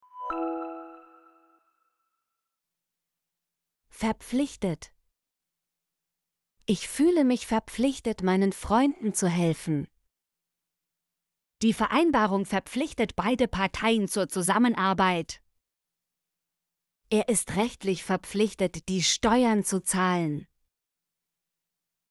verpflichtet - Example Sentences & Pronunciation, German Frequency List